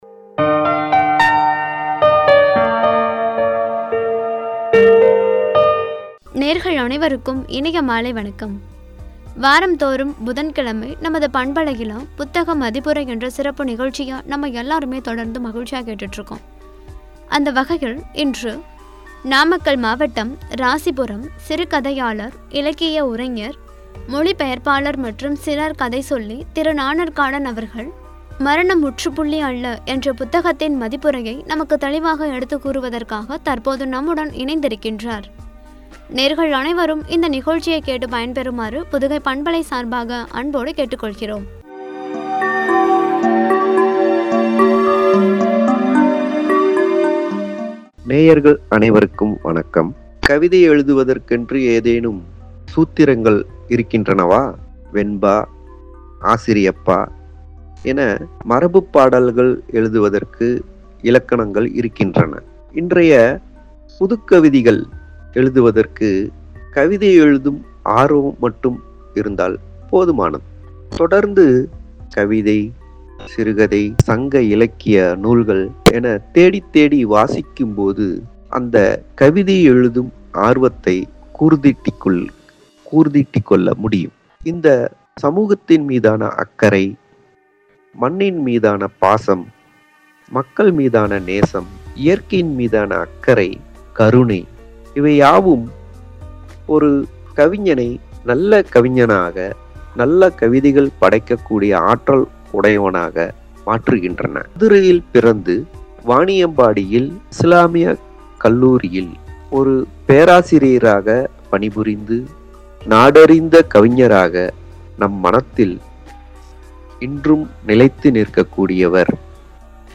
(புத்தக மதிப்புரை – பகுதி – 126) குறித்து வழங்கிய உரை.